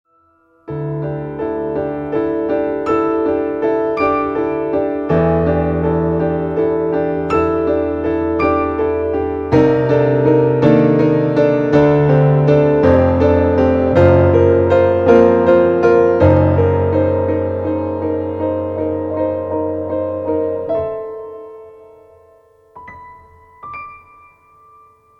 • Качество: 192, Stereo
спокойные
без слов
клавишные
инструментальные
пианино